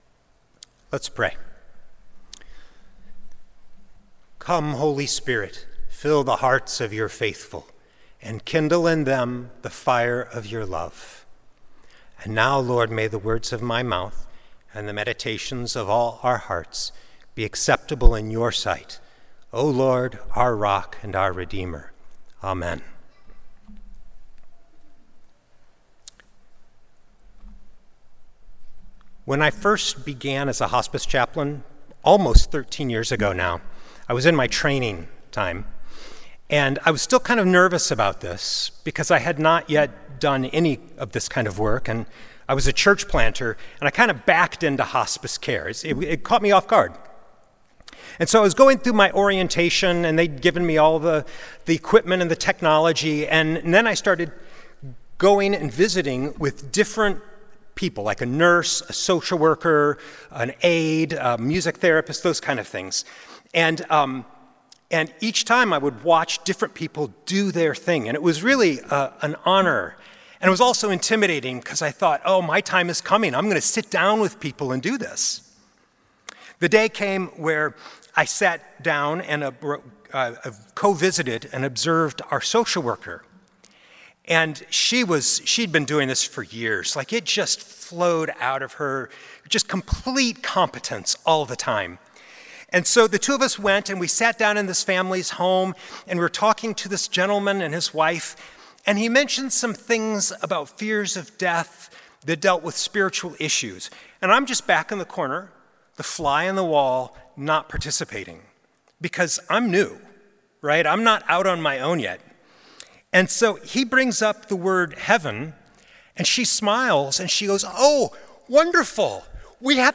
Sunday Worship–June 1, 2025
Sermons